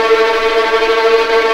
Index of /90_sSampleCDs/Roland L-CD702/VOL-1/STR_Vlns Tremelo/STR_Vls Tremolo